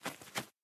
minecraft / sounds / mob / parrot / fly5.ogg
fly5.ogg